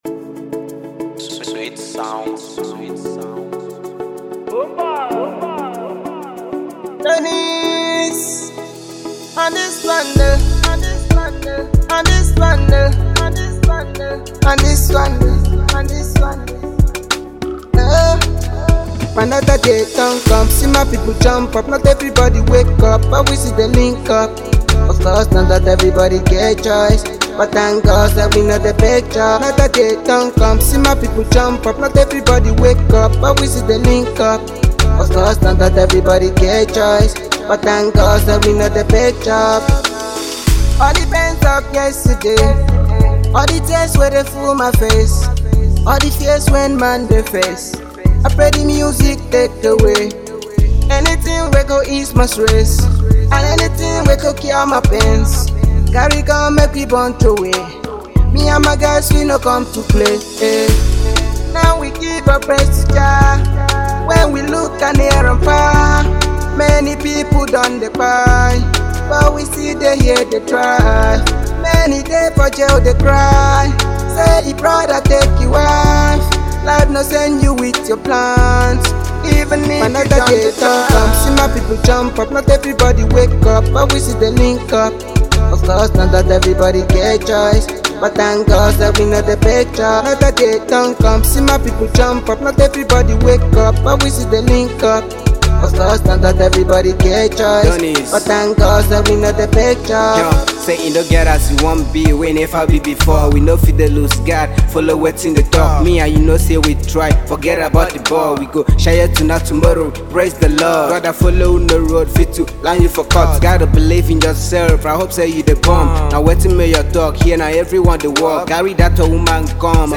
a Ghetto gospel with amazing feel.